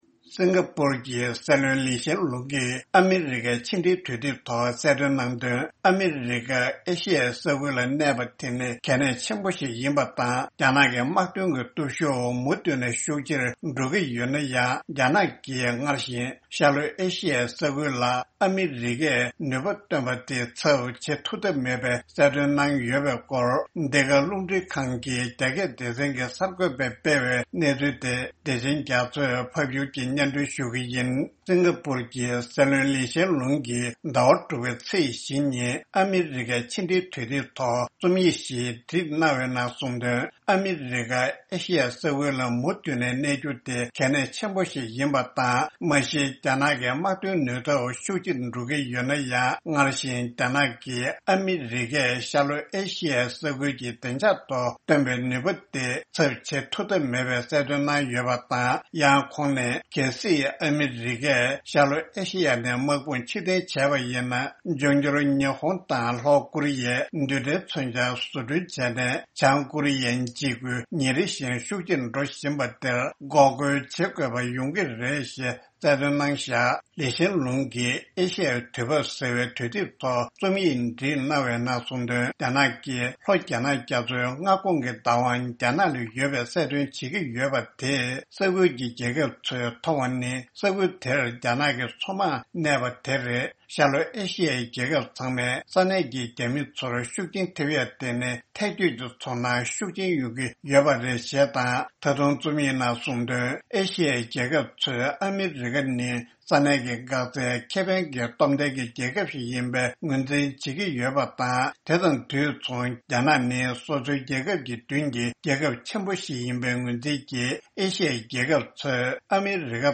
ཕབ་སྒྱུར་གྱིས་སྙན་སྒྲོན་ཞུ་ཡི་རེད།།